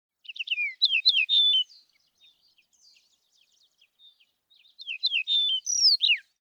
シマアオジ｜日本の鳥百科｜サントリーの愛鳥活動
「日本の鳥百科」シマアオジの紹介です（鳴き声あり）。